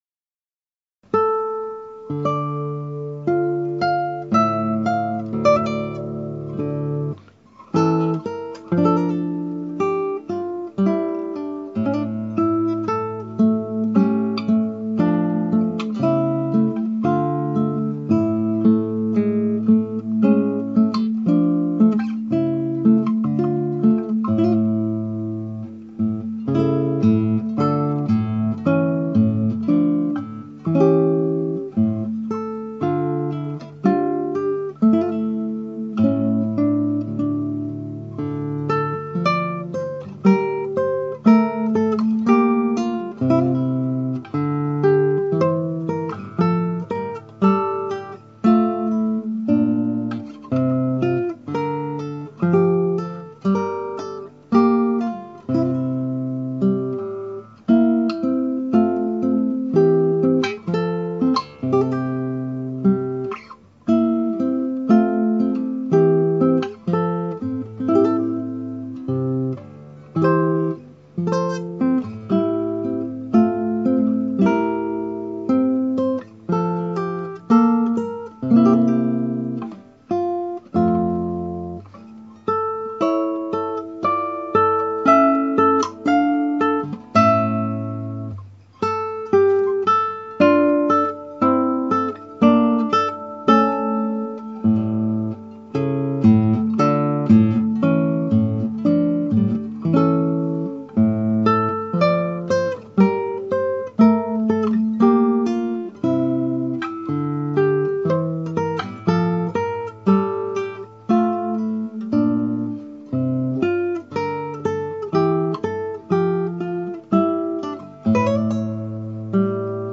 (アマチュアのクラシックギター演奏です [Guitar amatuer play] )
一生懸命、練習し録音したのですが私には難しくスラー指定を普通に弾いている所が多くあります。テンポももう少し速いほうが良いと思います。
あちこちミスタッチがあり雑音やら演奏に詰まってしまている所があります。
handel_aylesford_sonata.mp3